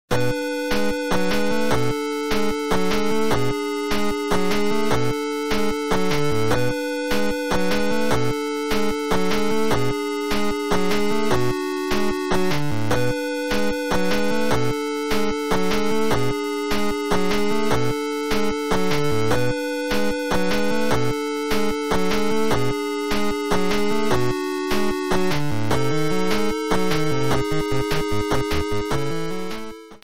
Trimmed to 30 seconds and applied fadeout